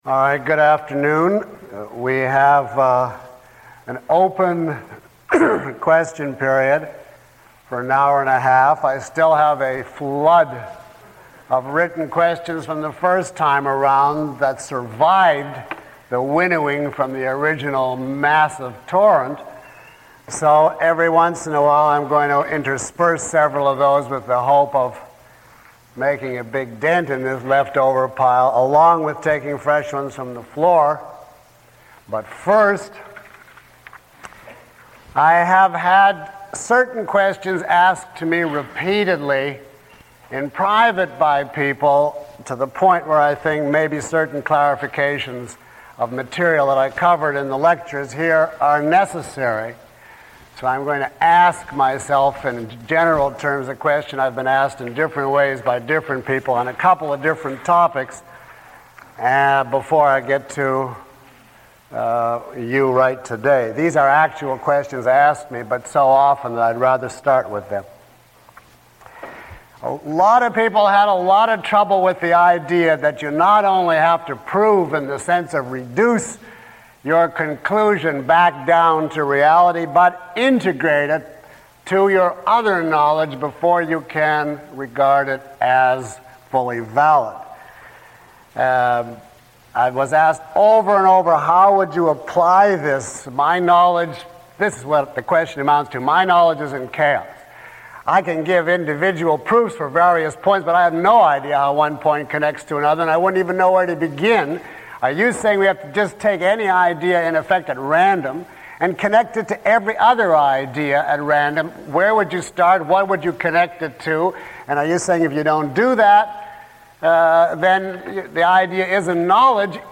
A question and answer session for course attendees.
Read more » In this lecture: A dedicated question and answer session with Dr. Peikoff.